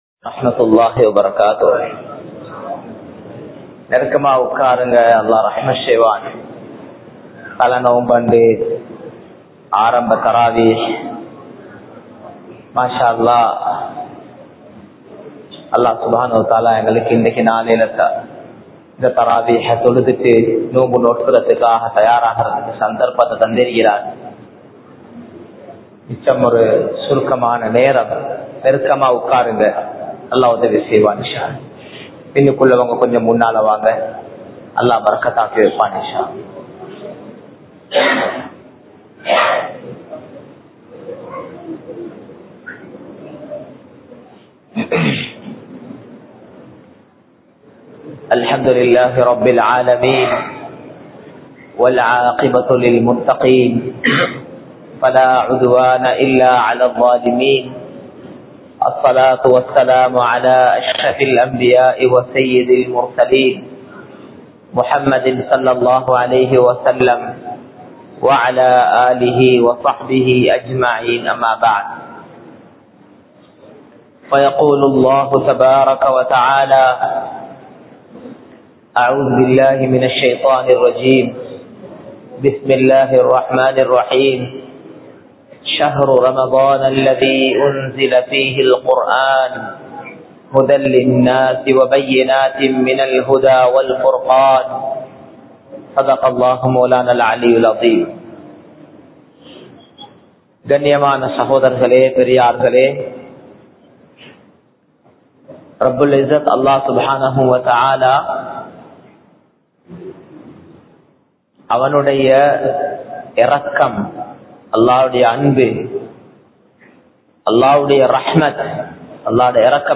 வாழ்க்கையை மாற்றும் ரமழான் | Audio Bayans | All Ceylon Muslim Youth Community | Addalaichenai
Muhiyadeen Jumua Masjidh